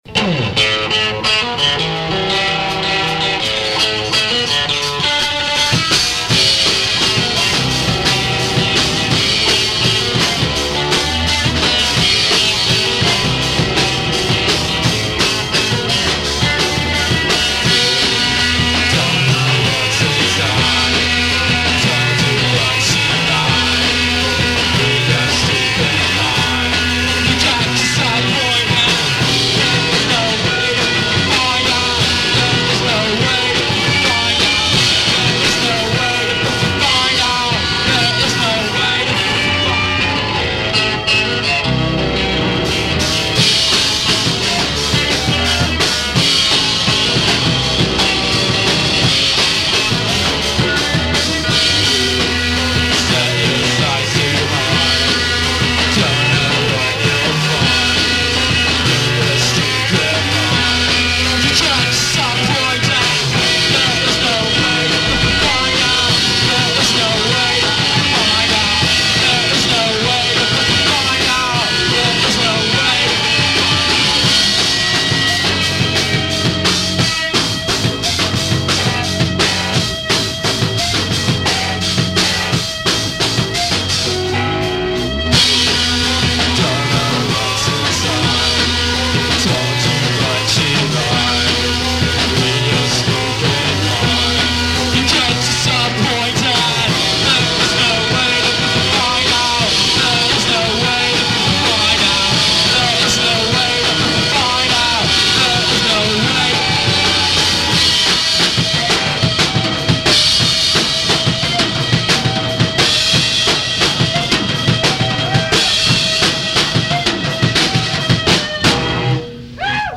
80’s new wave influenced bands